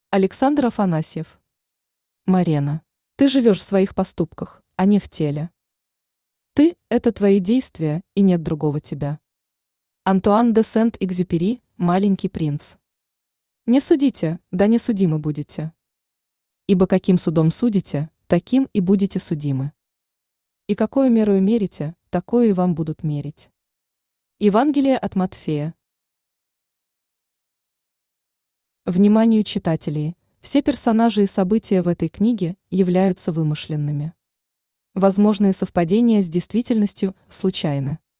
Аудиокнига Морена | Библиотека аудиокниг
Aудиокнига Морена Автор Александр Афанасьев Читает аудиокнигу Авточтец ЛитРес.